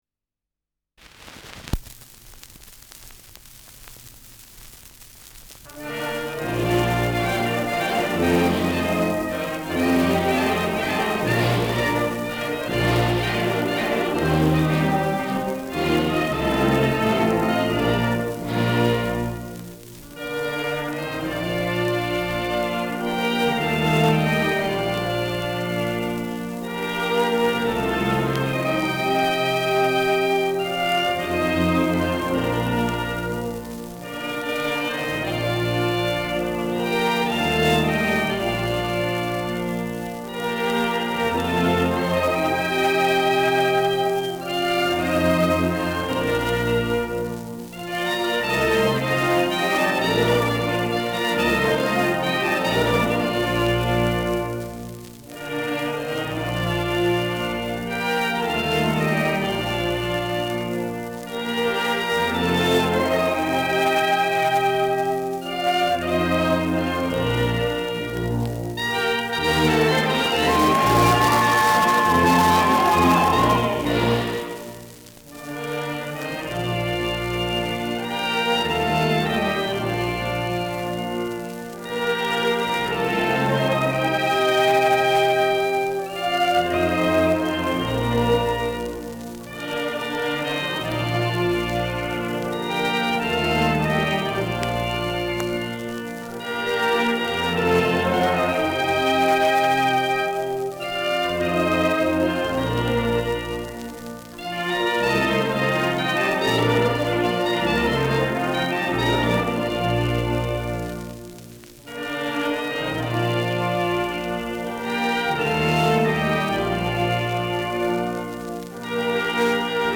Schellackplatte
präsentes Rauschen
Große Besetzung mit viel Hall, die einen „symphonischen Klang“ erzeugt.
[Berlin] (Aufnahmeort)